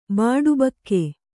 ♪ bāḍubakke